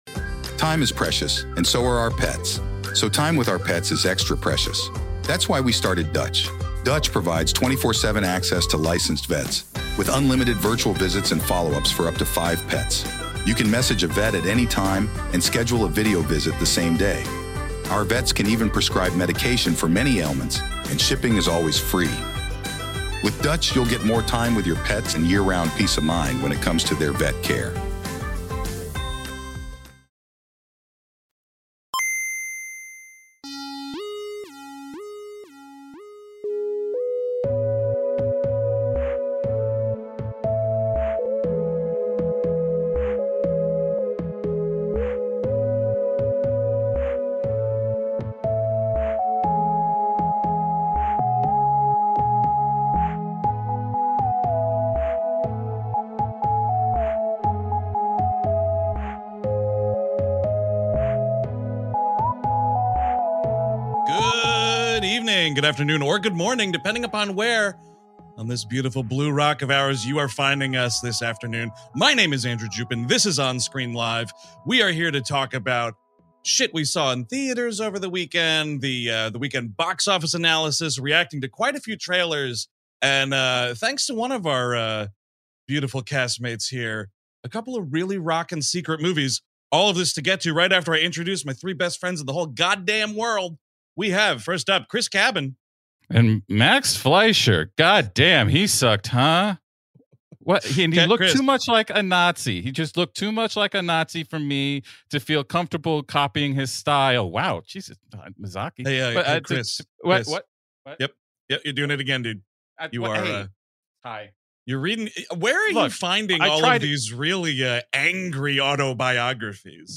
This is of course the audio-only edition of On-Screen Live! , if you want the full experience, check out the show on our YouTube channel.